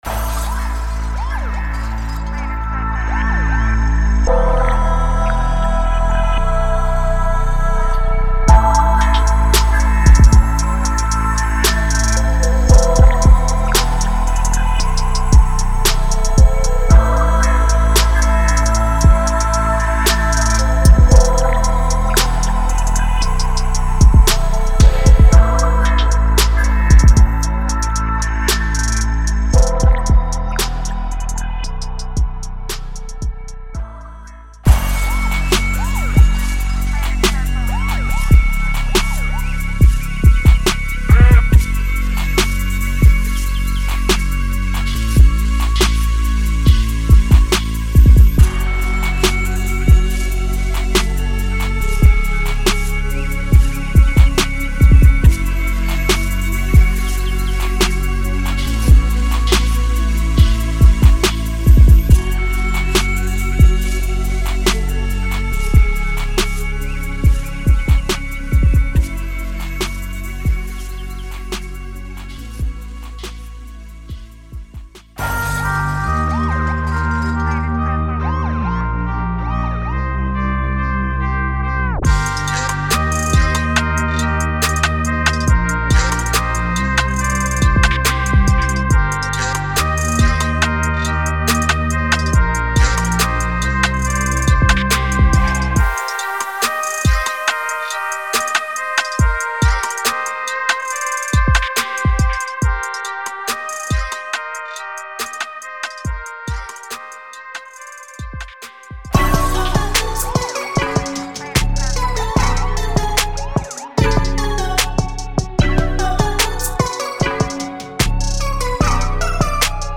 Hip HopTrap
•5个多轨Hip Hop施工套件